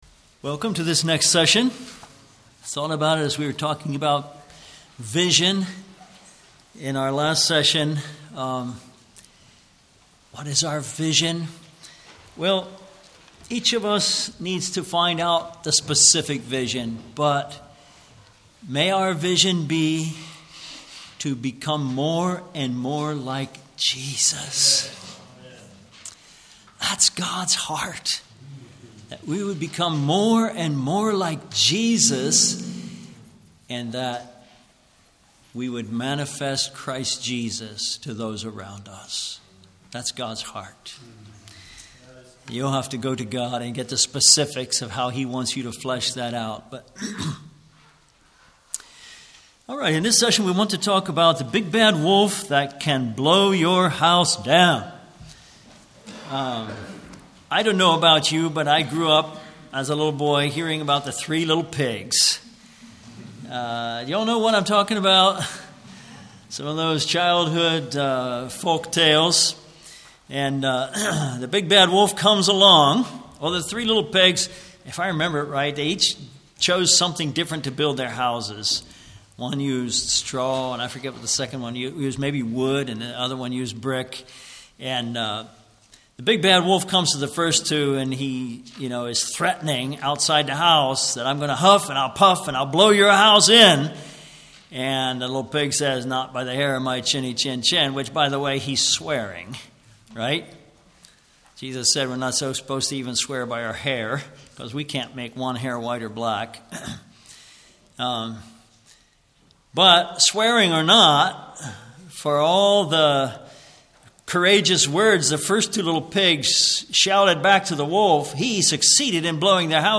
Service Type: Ladies Seminar, Men's Seminar